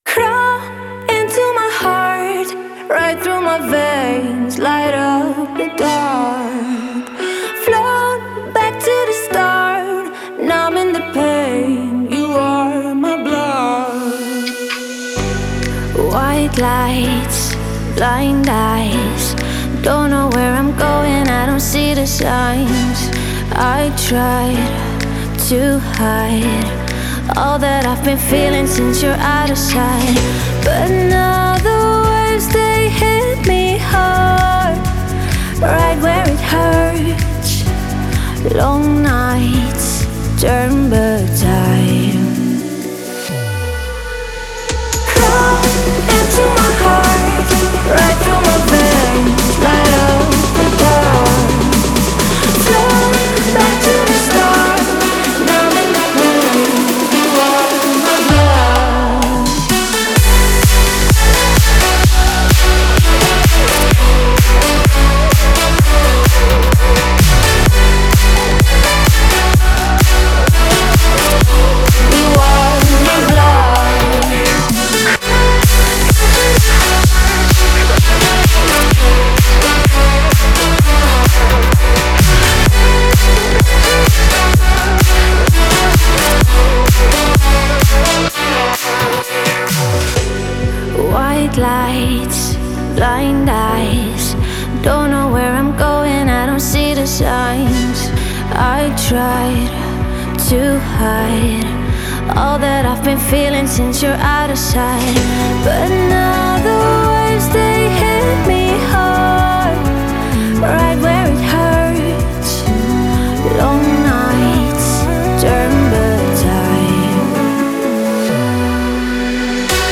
это энергичная электронная танцевальная композиция